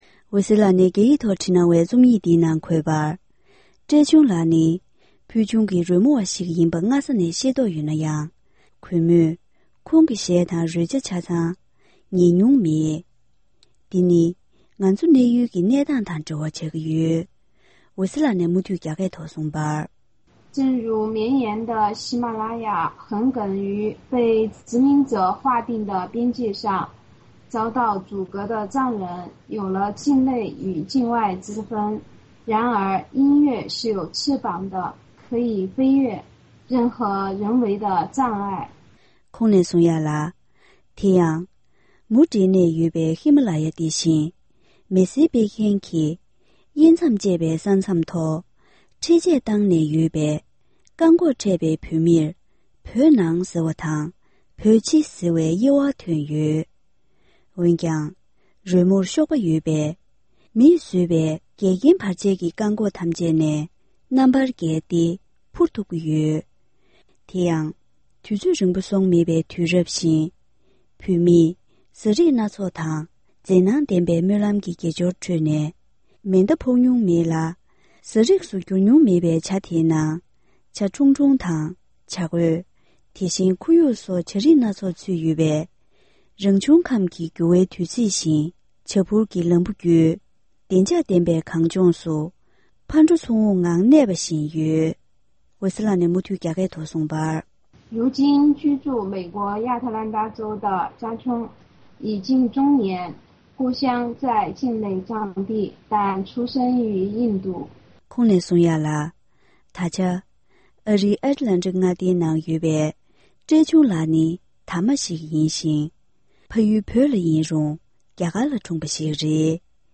ཕབ་བསྒྱུར་དང་སྙན་སྒྲོན་ཞུས་པར་གསན་རོགས་ཞུ༎